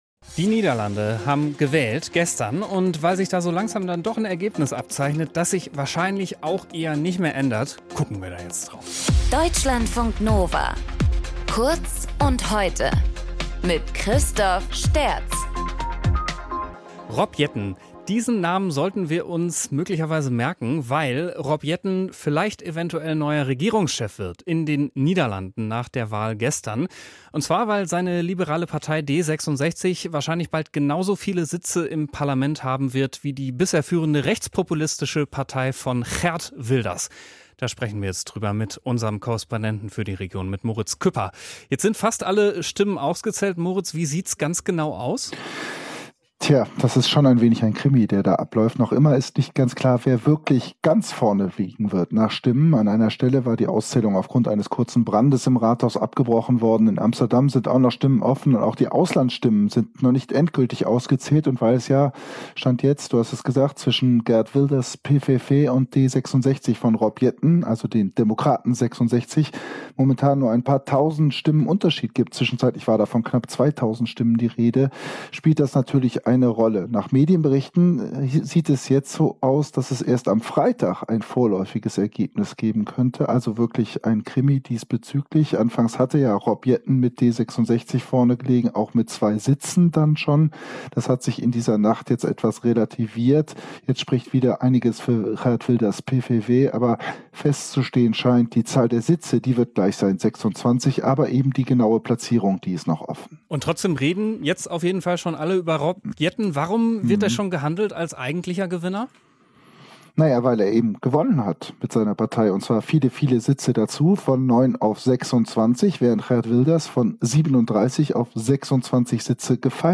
Moderator: